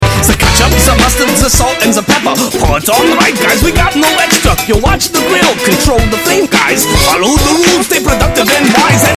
Music cut from the soundtrack